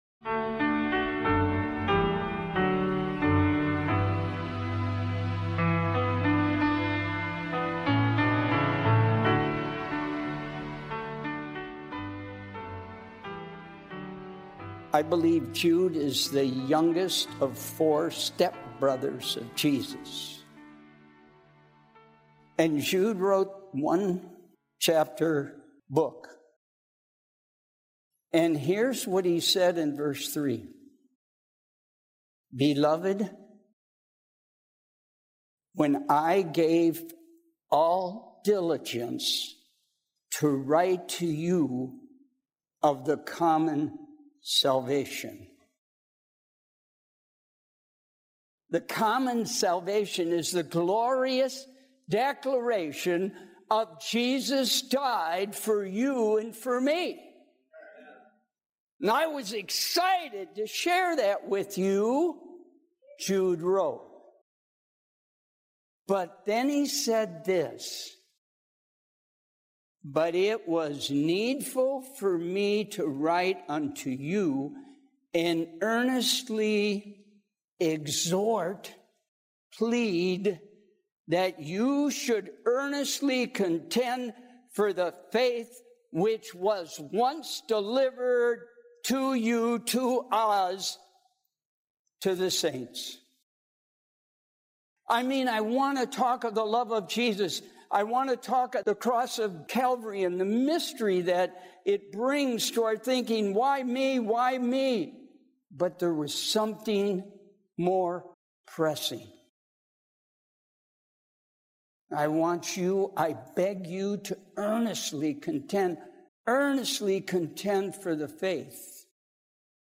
This sermon unpacks the deep meaning of the Day of Atonement, calling believers to earnestly contend for the faith through humility, repentance, and complete reliance on God. Connecting the ancient sanctuary service with Christ’s present heavenly ministry, it delivers a powerful end-time appeal for spiritual vigilance, communal worship, and readiness for judgment.